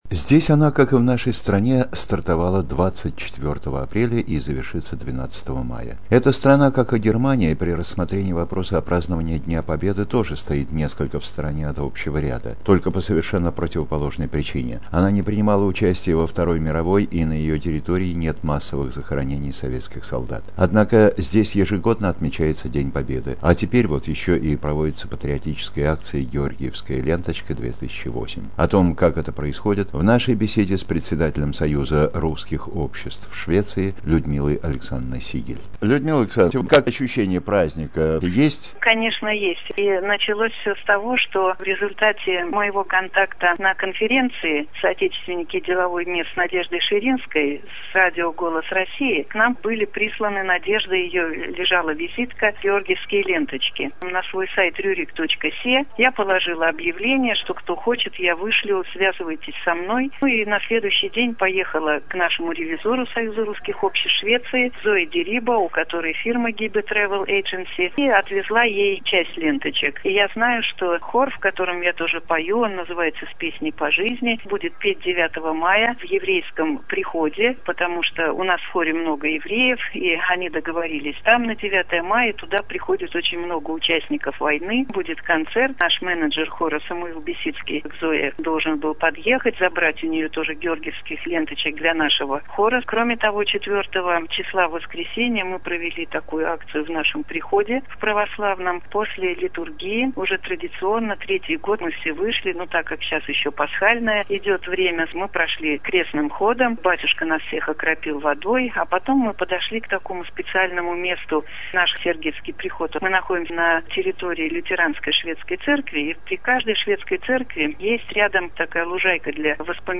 "ГЕОРГИЕВСКАЯ ЛЕНТОЧКА-2008" В ШВЕЦИИ"